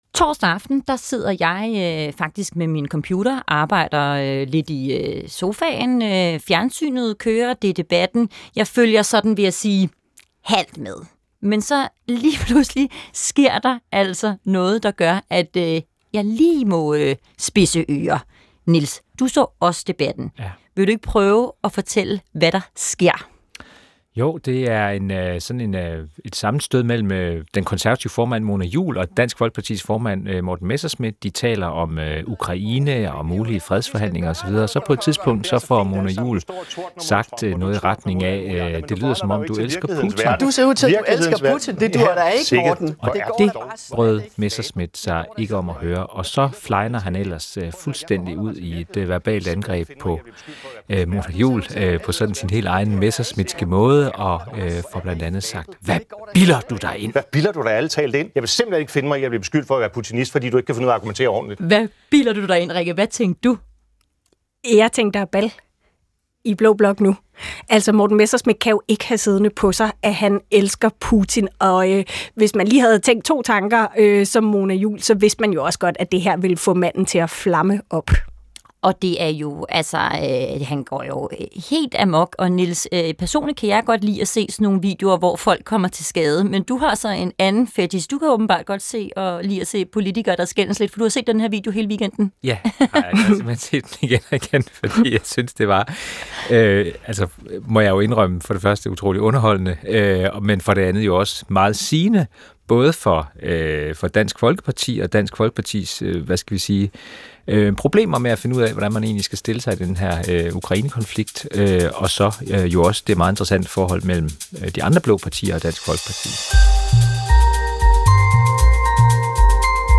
Bliv skarp på dansk politik. Få ugens vigtigste historier, kritiske interview og et indblik i de lukkede rum i DR's politiske podcast hver torsdag i DRLyd og på P1.